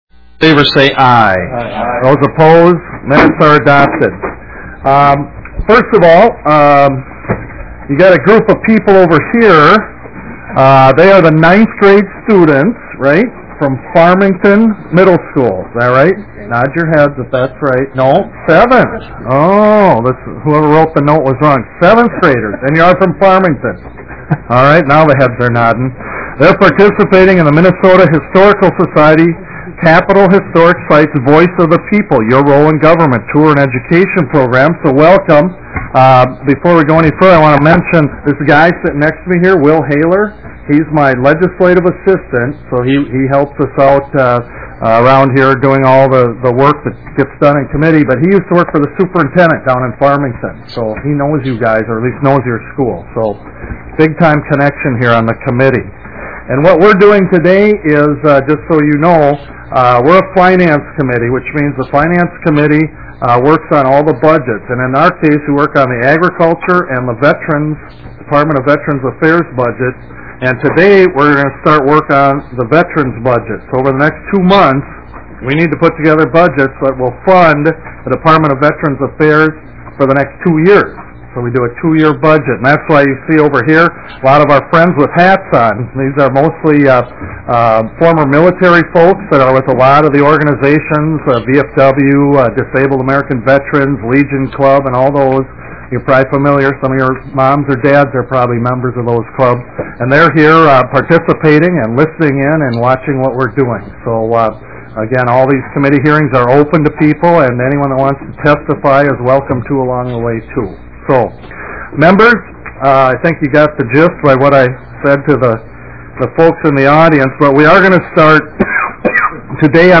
Representative Juhnke, Chair, called the meeting to order at 10:35 AM, February 17, 2009 in Room 5 of the State Office Building.
Discussion and questions followed.